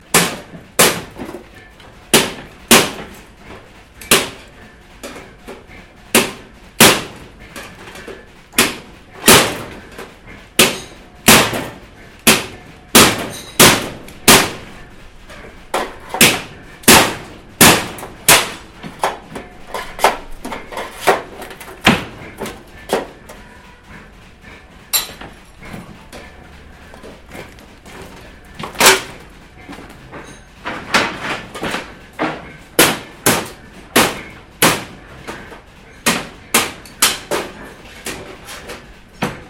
На этой странице собраны разнообразные звуки железной палки: от резких звонких ударов до протяжных вибраций.
Звук удара железной арматуры по железному щиту